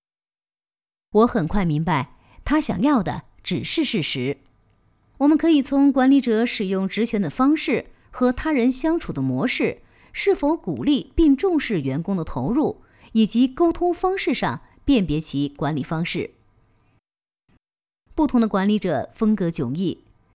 Chinese_Female_048VoiceArtist_2Hours_High_Quality_Voice_Dataset